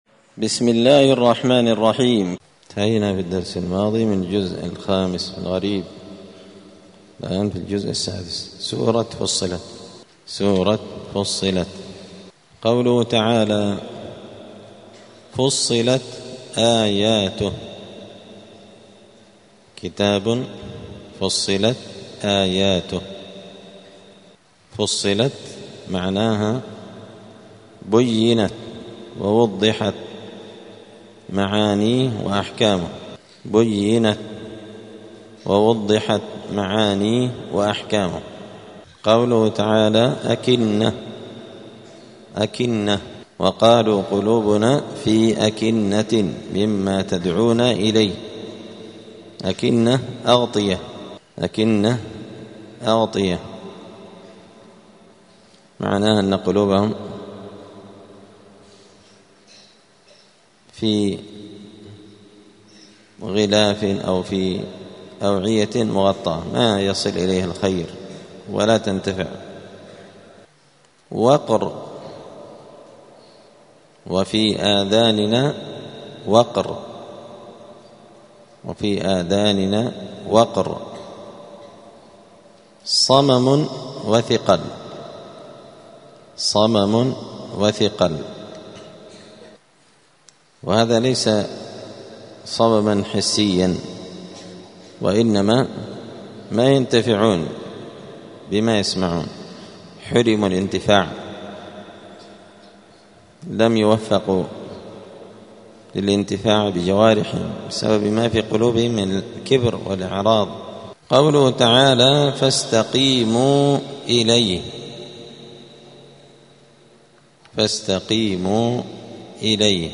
*(جزء فصلت سورة فصلت الدرس 215)*